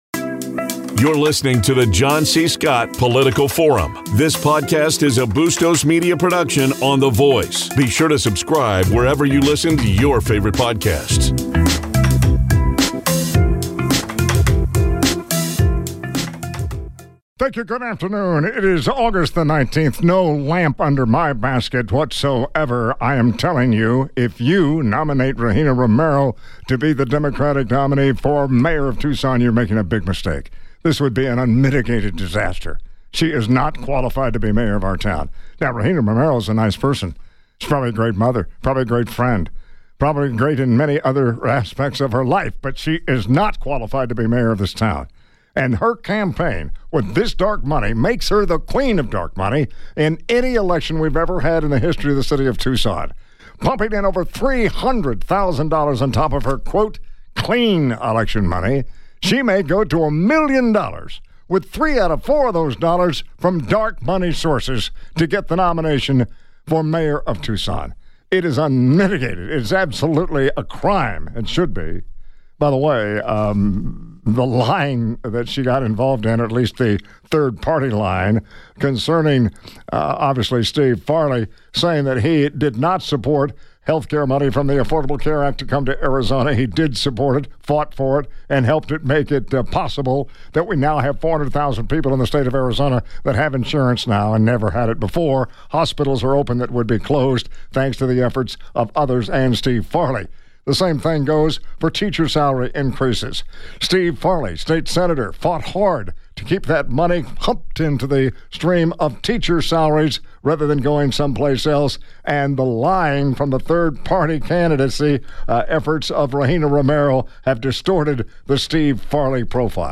He spoke on KVOI radio AM1030 on Aug. 19 .